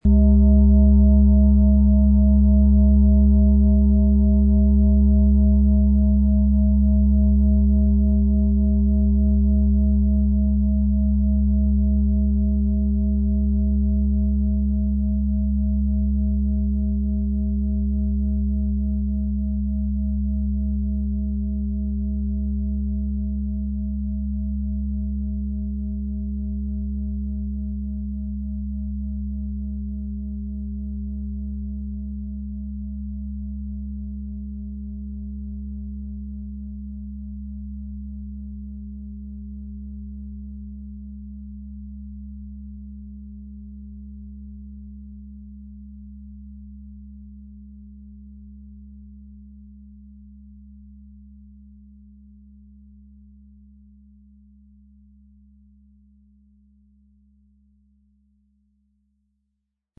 OM Ton
Die sanften Schwingungen des OM-Planetentons fördern eine tiefe, beruhigende Wirkung.
Der tiefe, gleichmäßige Ton schafft eine Atmosphäre der Ruhe und fördert Selbstheilungskräfte auf energetischer Ebene.